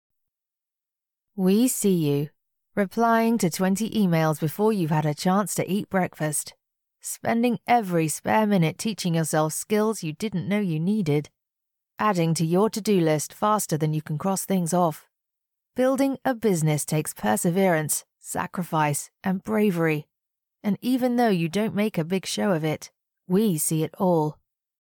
Female
Radio Commercials
British Accent I We See You
Words that describe my voice are Conversational, Believable, Engaging.
All our voice actors have professional broadcast quality recording studios.